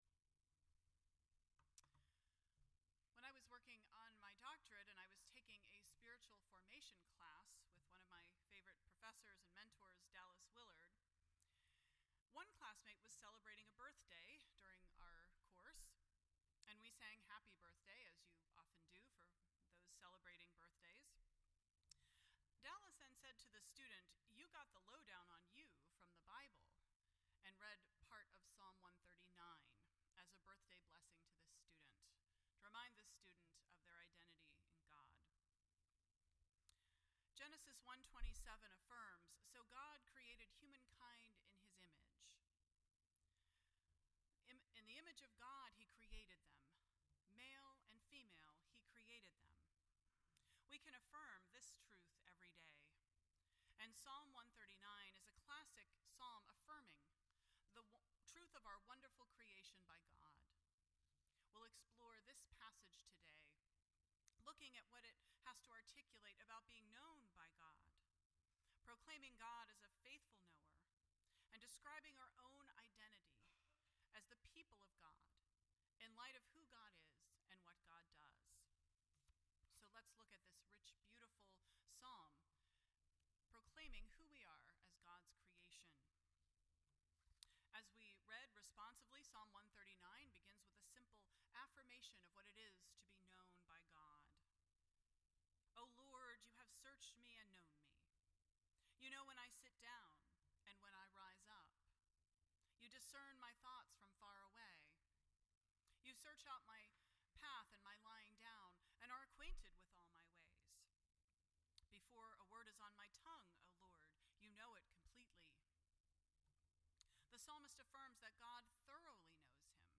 None Service Type: Sunday Morning %todo_render% Share This Story